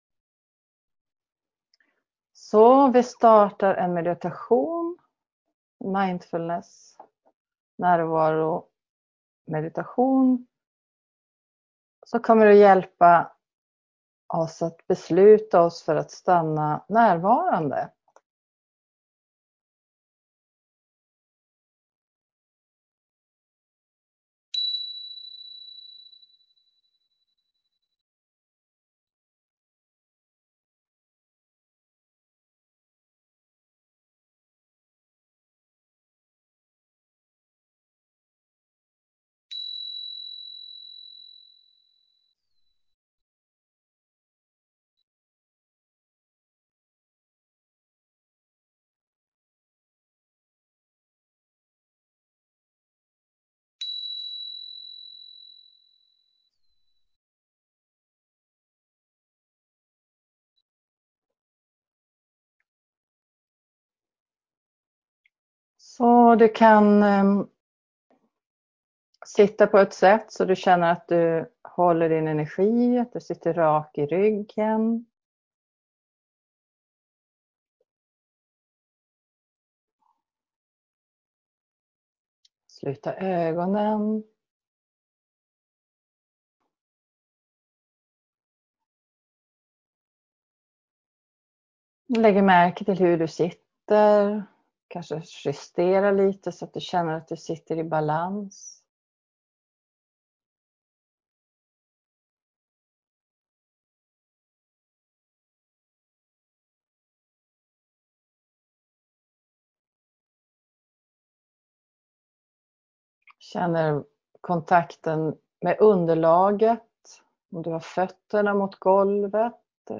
En gratis mindfulness meditation (22 min)